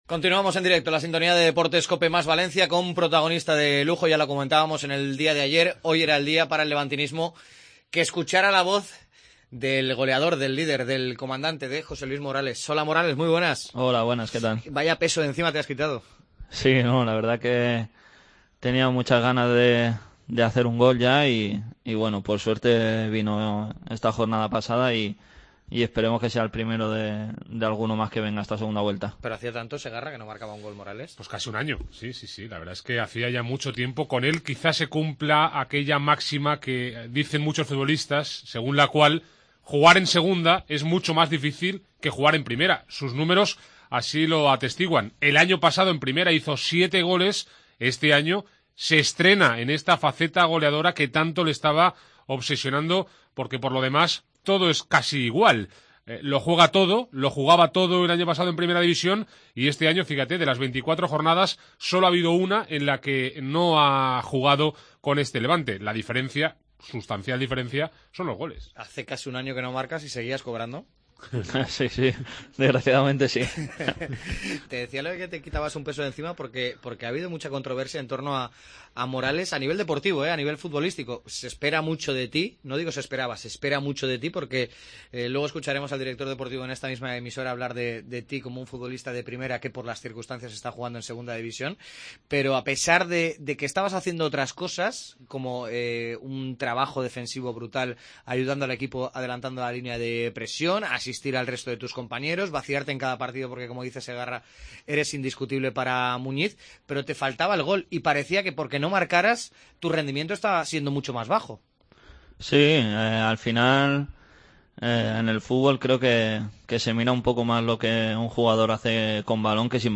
El jugador del Levante, que rompió ante el Nàstic una racha de nueve meses sin marcar, ha repasado en COPE Valencia la actualidad del Levante y ha declarado amor eterno al equipo granota.